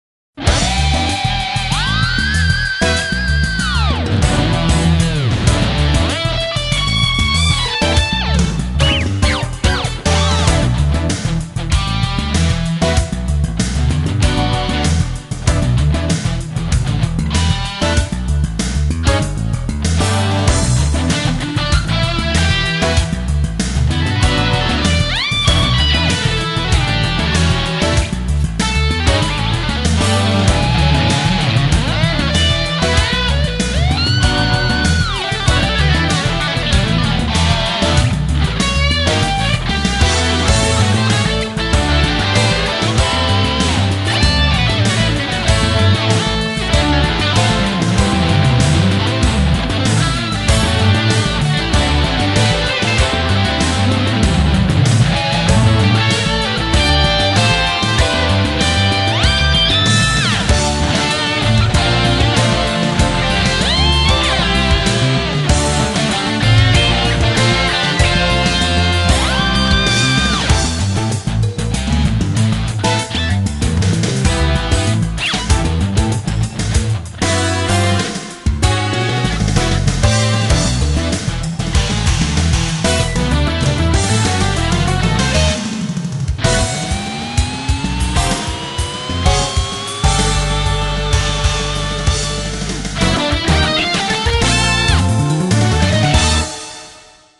(Guitar inst.)